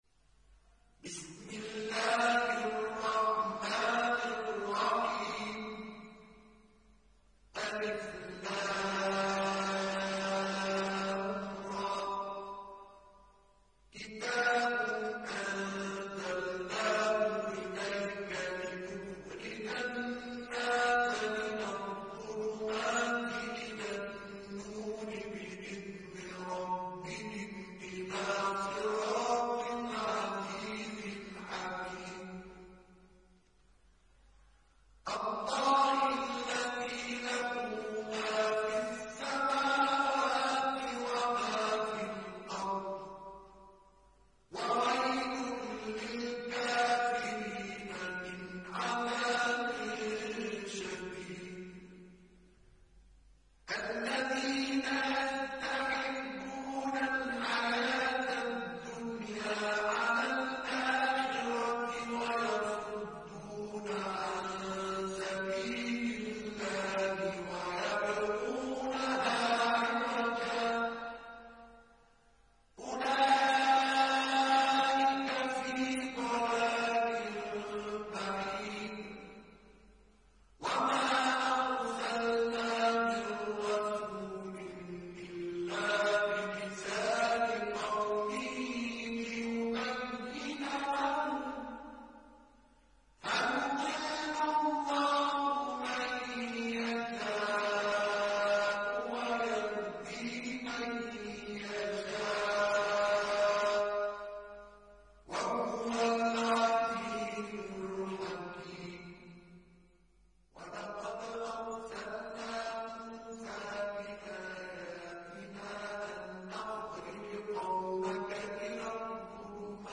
Mojawad